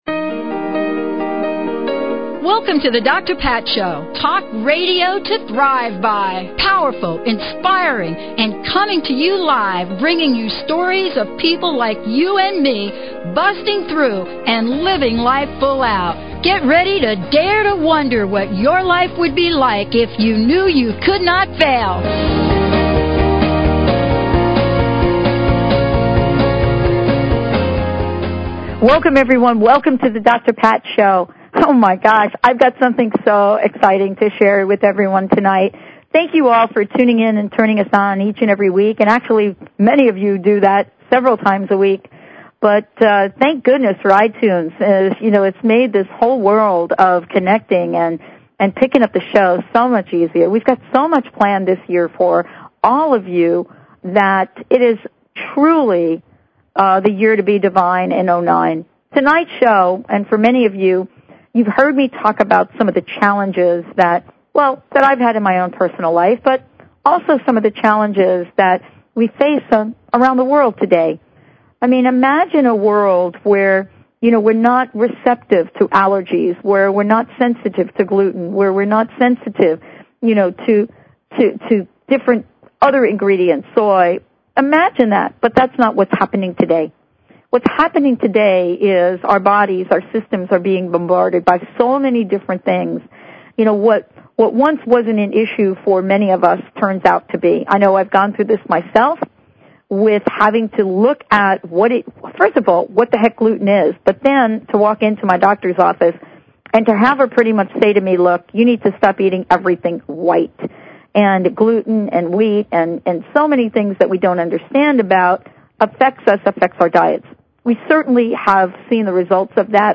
Talk Show Episode, Audio Podcast
Courtesy of BBS Radio